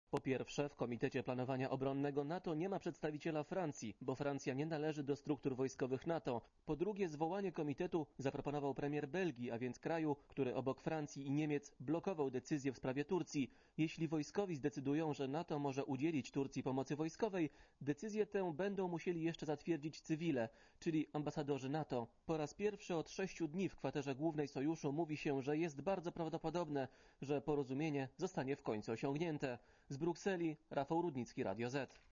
Posłuchaj relacji korespondenta Radia Zet (1 MB)